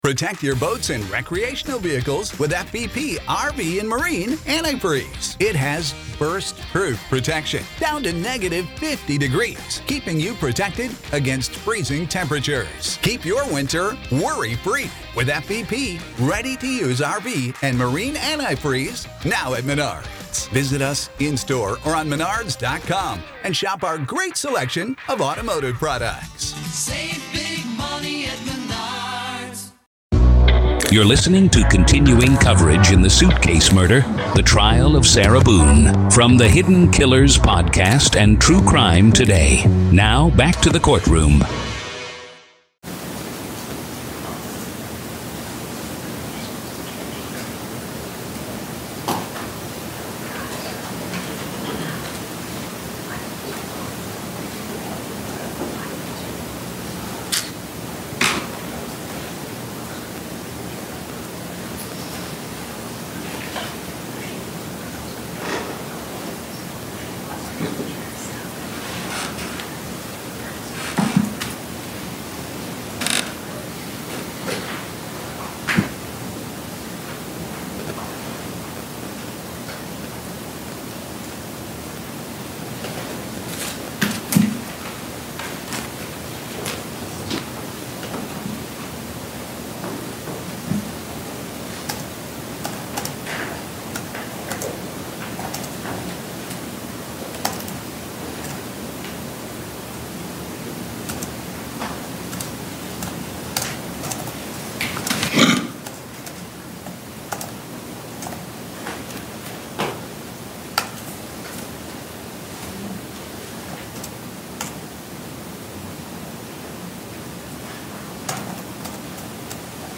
RAW COURT AUDIO